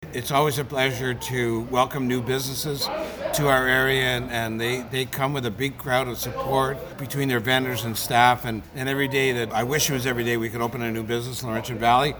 Laurentian Valley Mayor Steve Bennet had this to say after cutting the red ribbon: